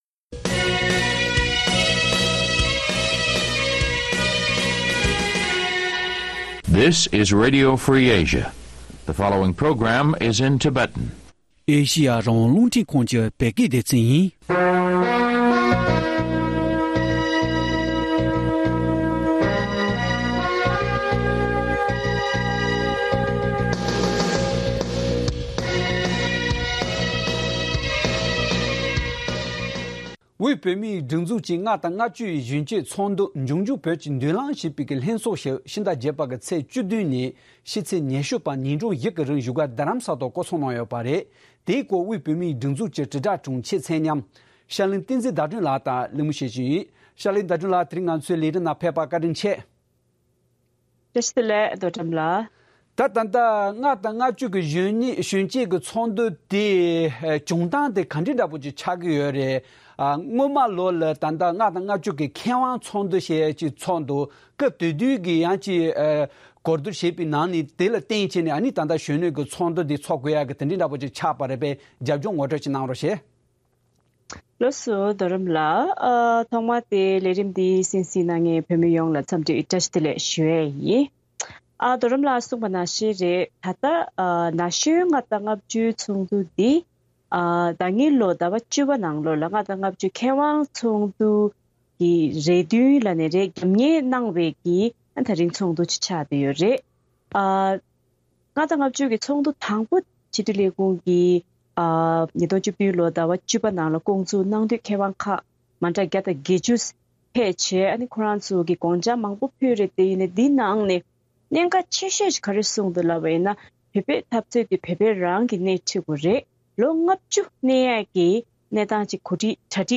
བཅའ་འདྲི་ཞུས་པར་གསན་རོགས